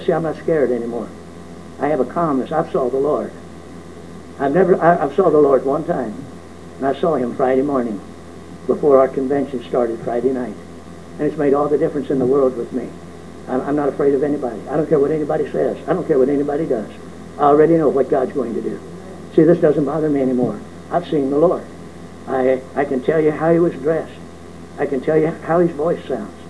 Again, same sermon.